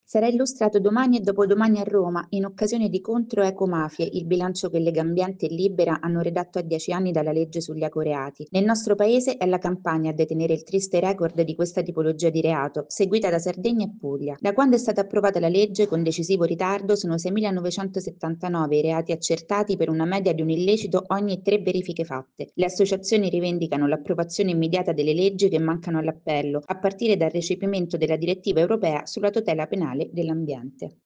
Sono passati dieci anni dall’approvazione della legge sugli ecoreati. La fotografia di Legambiente e Libera nel servizio